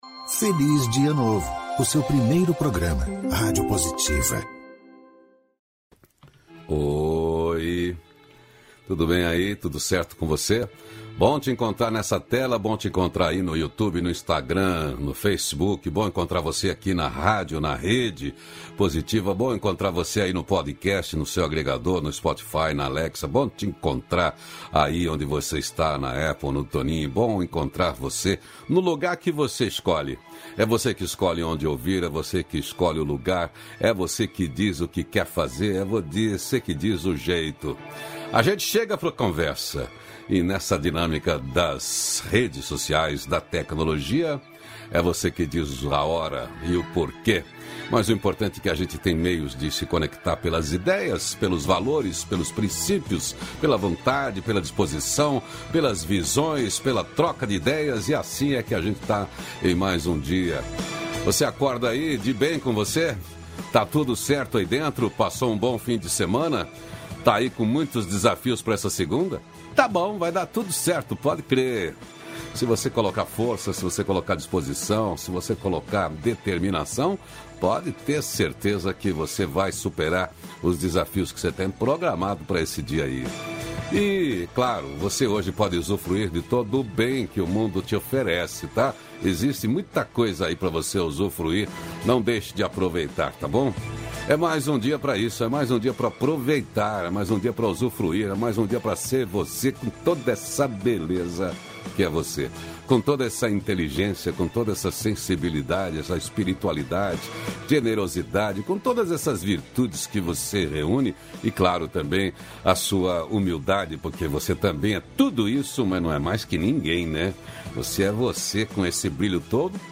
No diálogo de hoje